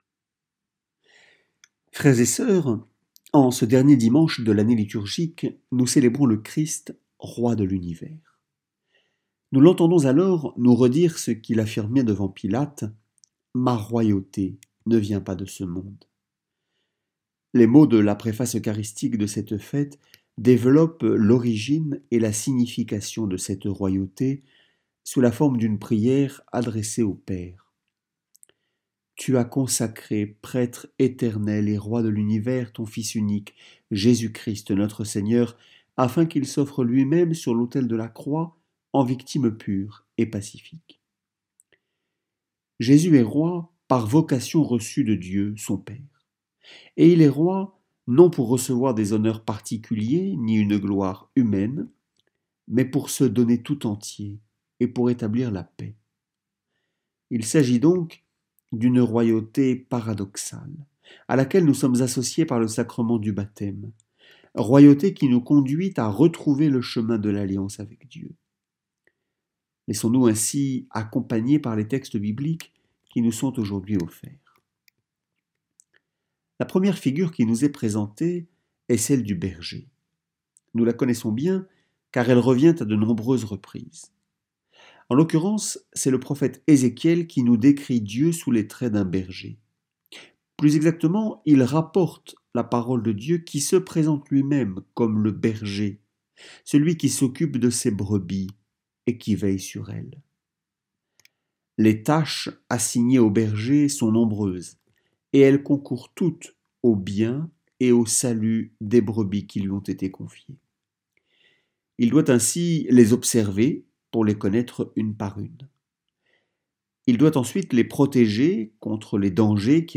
Homélie-Christ-Roi.mp3